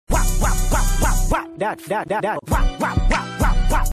Wow Wop Wop Wop Wop sound effects free download